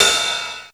MPC2 CYMB1.wav